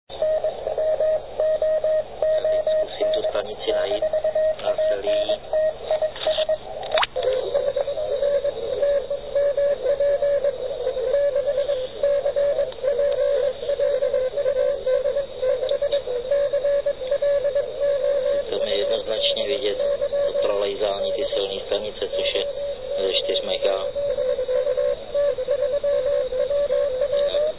Doma jsem pak porovnával " na ucho " signály slyšitelné na FT900 s CW filtrem 250 Hz a ten samý signál na Selii.